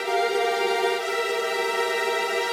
GS_Viols_95-G2.wav